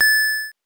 coin_1.wav